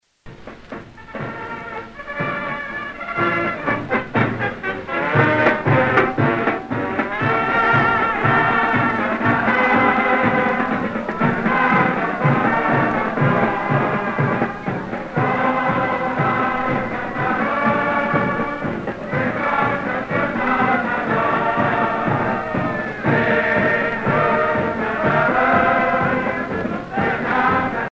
gestuel : à marcher
circonstance : militaire